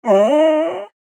Minecraft Version Minecraft Version snapshot Latest Release | Latest Snapshot snapshot / assets / minecraft / sounds / mob / wolf / cute / death.ogg Compare With Compare With Latest Release | Latest Snapshot
death.ogg